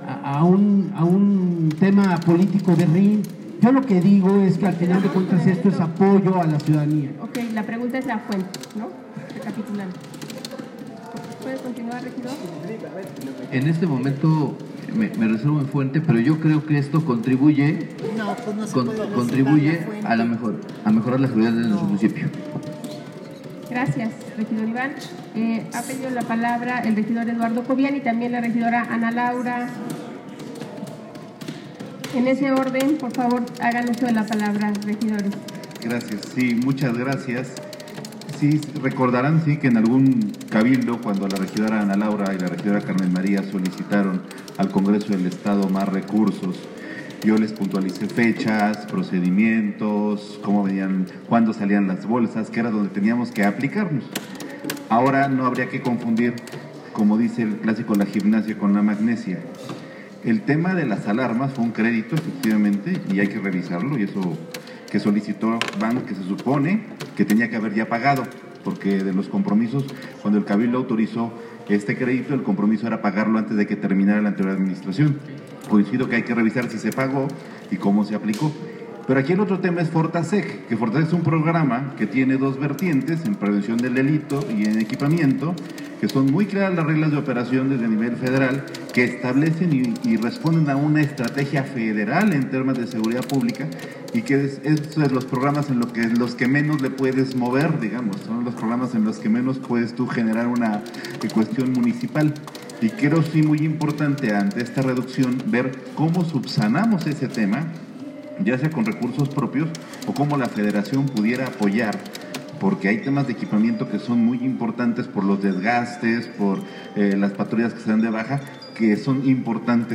En sesión ordinaria del Cabildo, el cuerpo edilicio aprobó por unanimidad la entrega del informe del análisis de los recursos que se destinaron para el año 2019 del programa de Fortalecimiento pata la Seguridad (Fortaseg), cantidad que disminuyó de 82.1 millones de pesos a 48.6 millones; lo que representa el 41 por ciento menos del recurso que se aplicarán al rubro de seguridad pública, punto que presentó el regidor del PAN, Enrique Guevara Montiel.